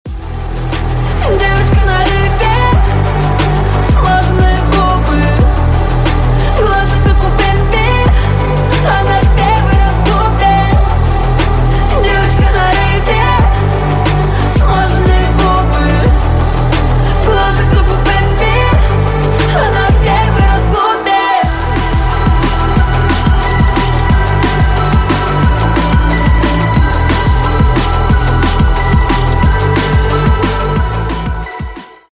Клубные Рингтоны
DnB Рингтоны
Танцевальные Рингтоны